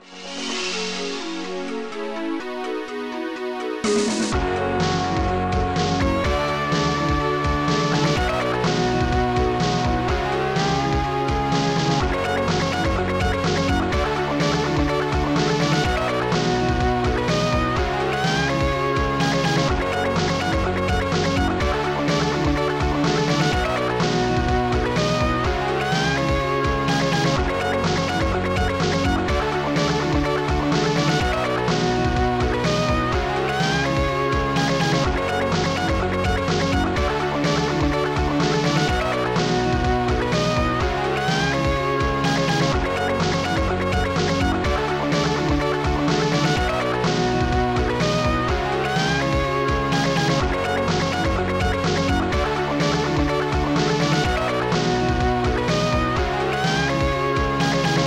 FastTracker Module